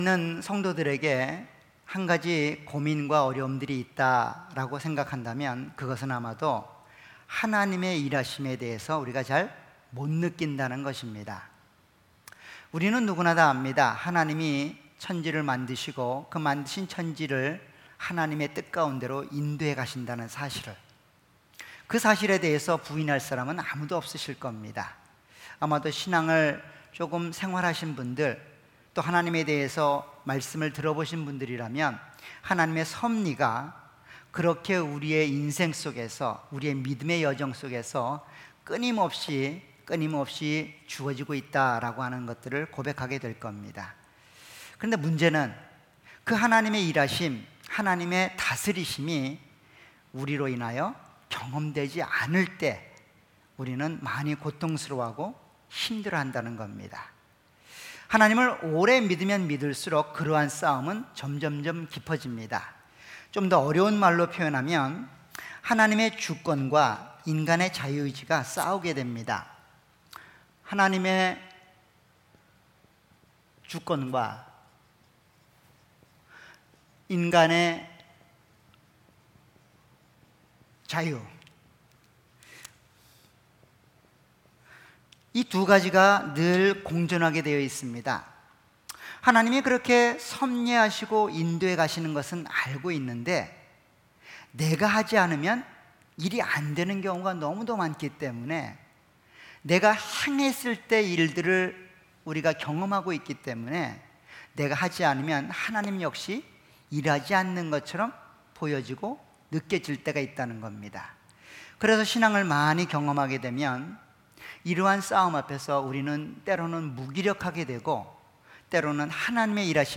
Series: 수요예배.Wednesday